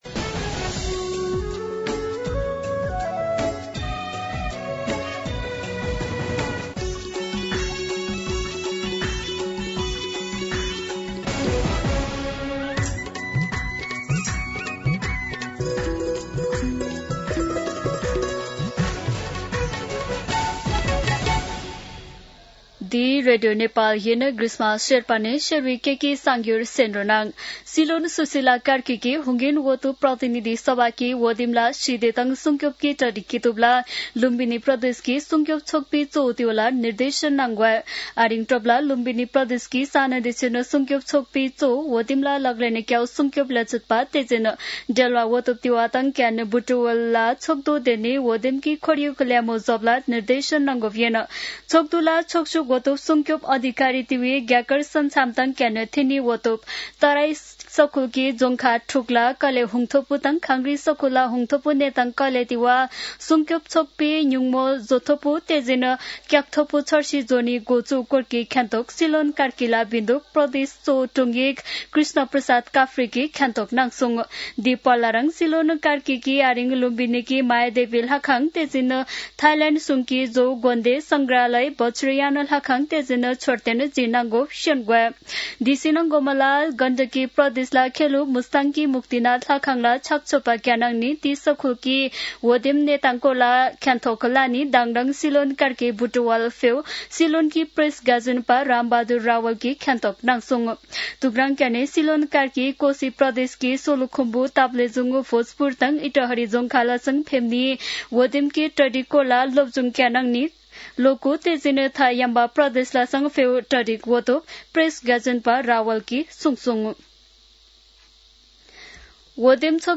शेर्पा भाषाको समाचार : ९ फागुन , २०८२
Sherpa-News-09-1.mp3